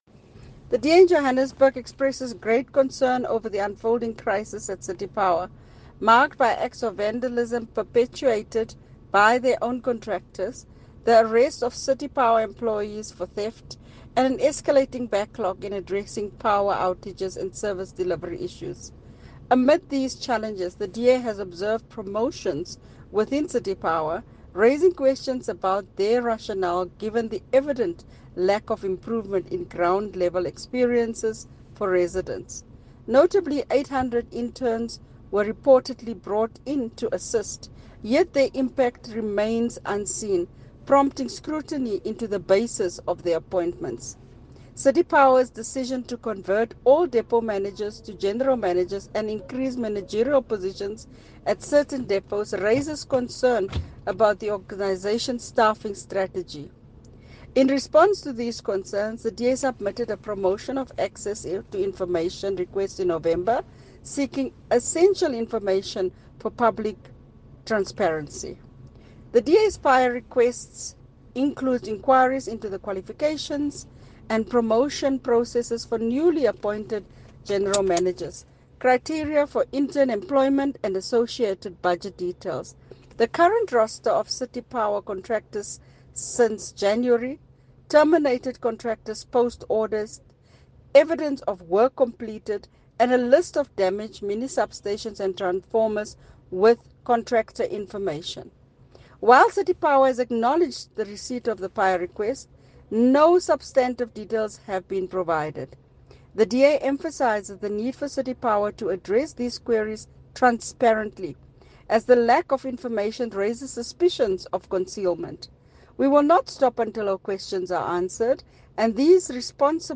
Issued by Cllr Belinda Kayser-Echeozonjoku – DA Johannesburg Caucus Leader
Note to Editors: Please find a soundbite